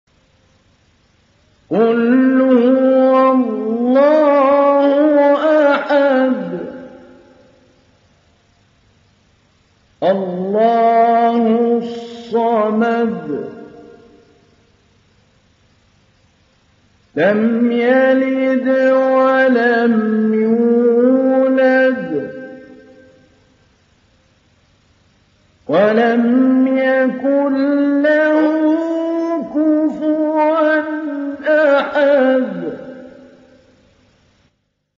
تحميل سورة الإخلاص mp3 بصوت محمود علي البنا مجود برواية حفص عن عاصم, تحميل استماع القرآن الكريم على الجوال mp3 كاملا بروابط مباشرة وسريعة
تحميل سورة الإخلاص محمود علي البنا مجود